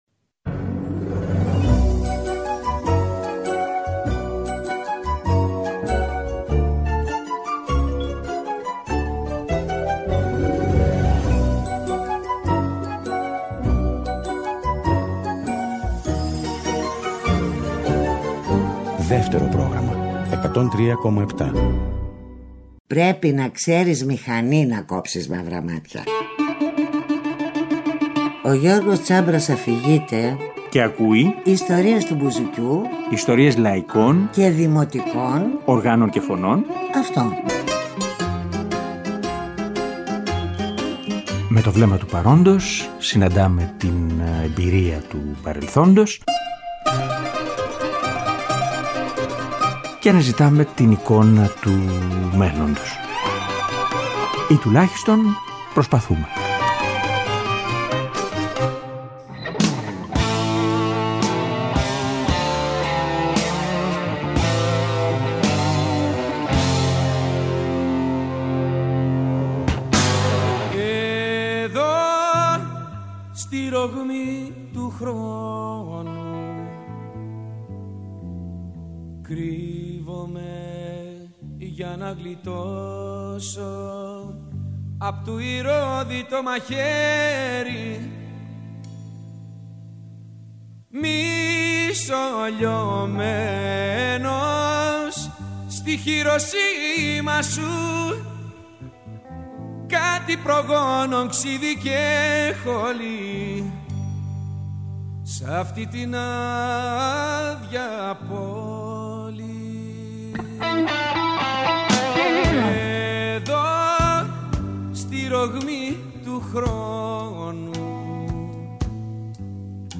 ξανακούμε σε δύο εκπομπές, τραγούδια